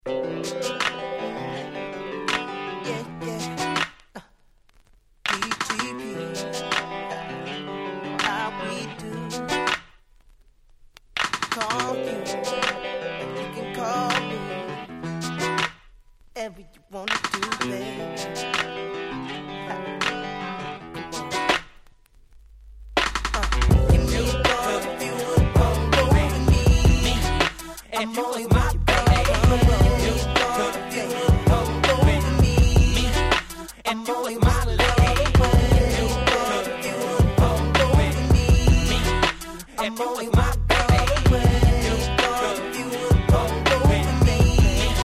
04' Smash Hit Hip Hop !!
サビに男性Vocalを迎えた、切ないメロディーの堪らない人気曲！！
South サウス キラキラ系